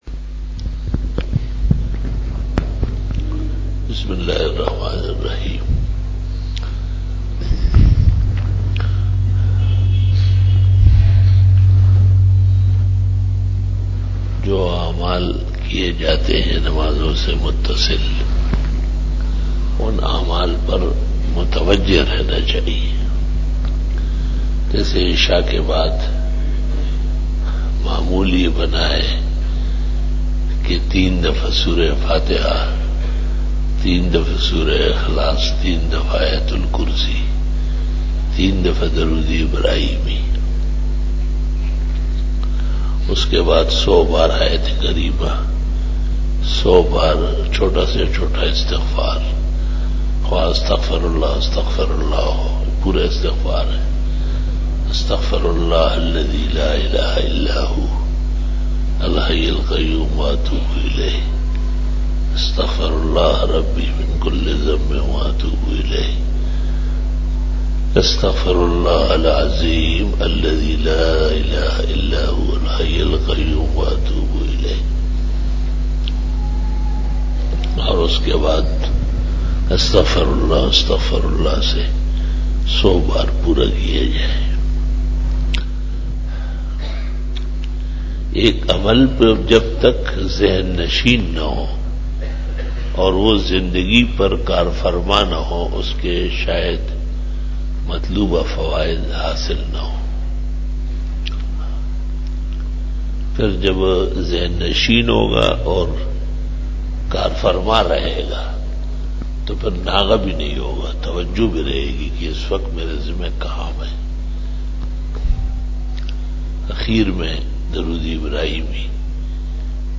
After Namaz Bayan